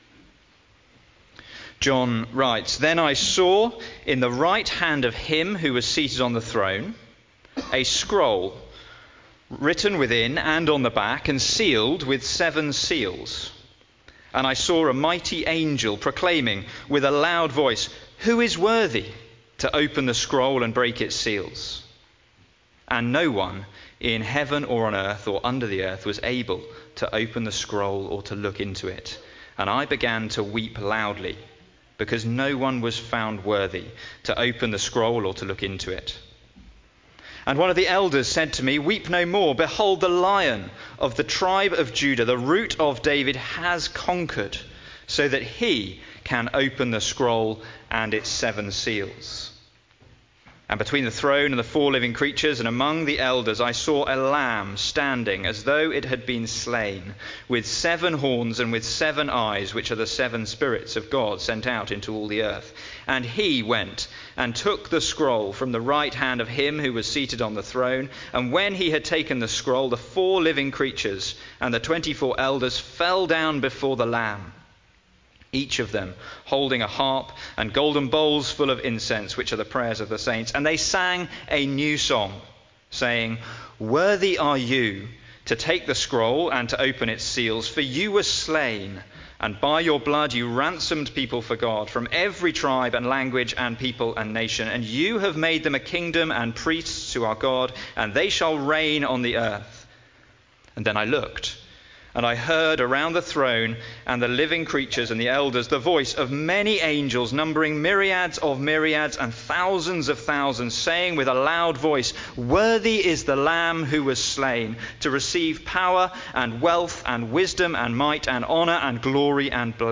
Sermon
good-friday-2025.mp3